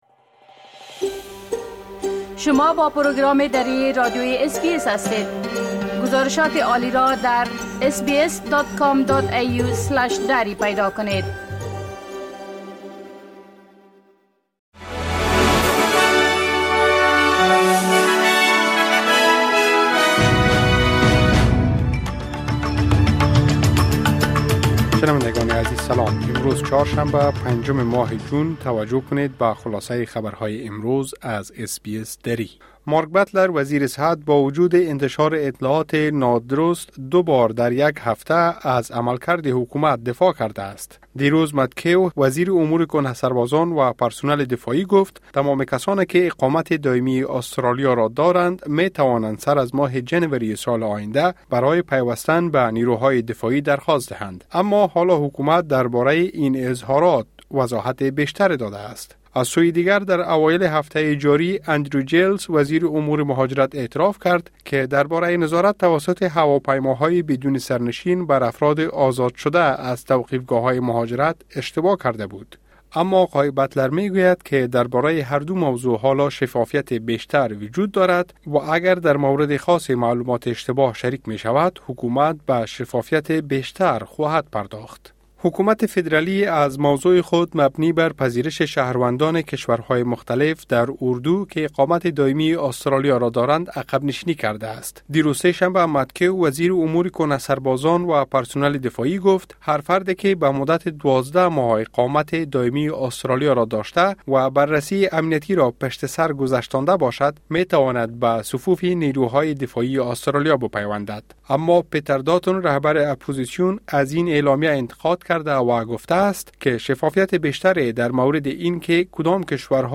خلاصۀ مهمترين خبرهای روز از بخش درى راديوى اس بى اس|۵ جون ۲۰۲۴